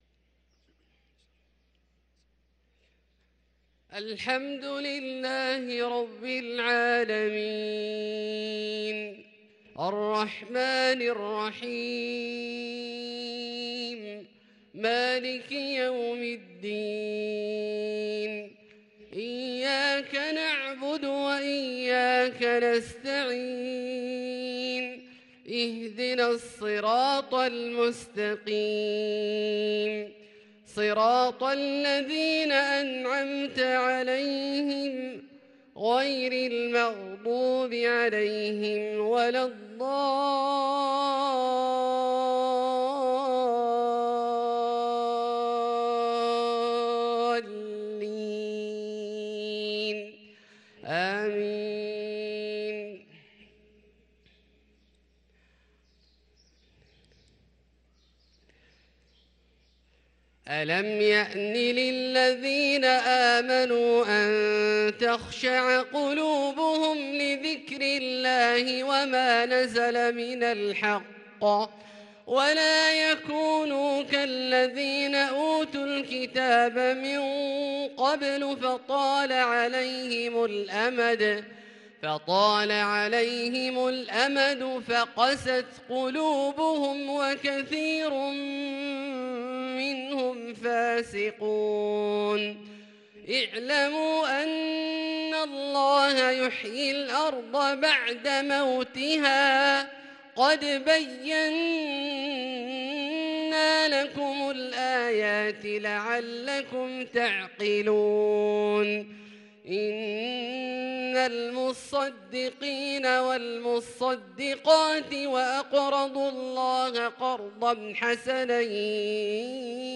صلاة العشاء للقارئ عبدالله الجهني 11 جمادي الآخر 1444 هـ